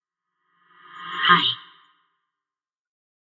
Male Vocal Reactions » Sigh 5 Male Deep
描述：A young male sighing, possibly in frustration, exasperation, boredom, anger, etc. Recorded using my Turtlebeach EarforceX12 headset and then edited in Audacity.
标签： man male human deep speech breath vocal sigh baritone voice reaction breathe